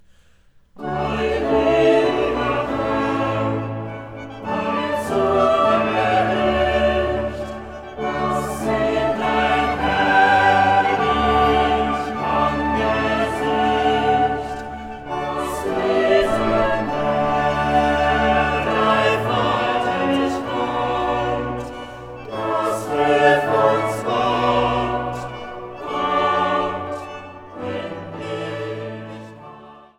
Kantate